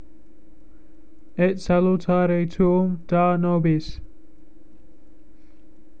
Ett sah-loo-tah-ray too-oom  dah   no-biss.